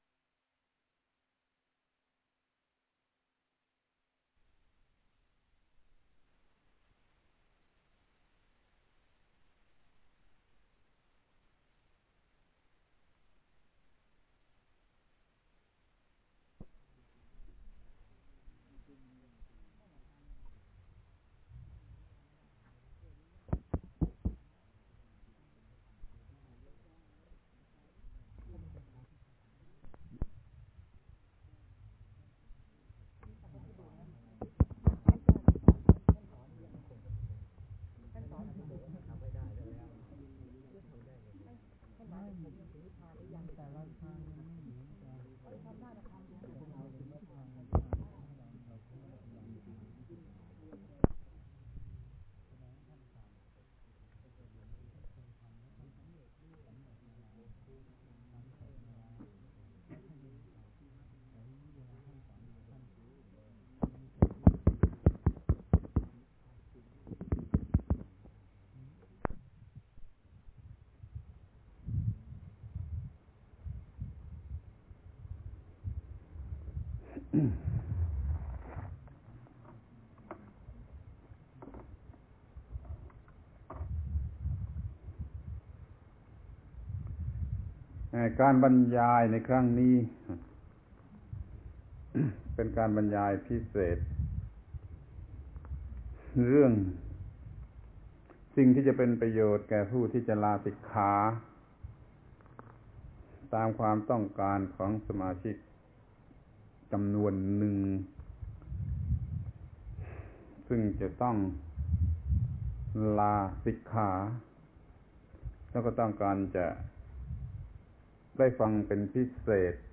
พระธรรมโกศาจารย์ (พุทธทาสภิกขุ) - อบรมพระนิสิต ม.มหิดล ชุด เตกิจฉกธรรม ปี 2514 ครั้งที่ 15 โอวาทลาสิกขา
เออ, การบรรยายในครั้งนี้ อ่ะ, เป็นการบรรยายพิเศษ เรื่อง สิ่งที่จะเป็นประโยชน์แก่ผู้ที่จะ ลาสิกขา ตามความต้องการของสมาชิก จำนวนหนึ่ง ซึ่งจะต้อง ลาสิกขา แล้วก็ต้ ...